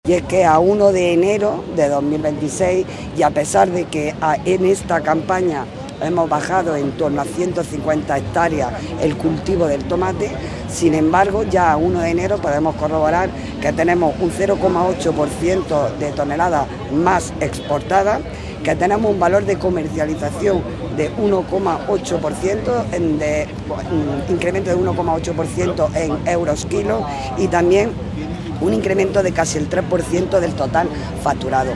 La alcaldesa, el presidente de Diputación y la delegada de la Junta asisten a la XII edición de un multitudinario evento cuya recaudación se destina a ANDA y Fundación Poco Frecuente
ARANZAZU-MARTIN-DELEGADA-GOBIERNO-JUNTA.mp3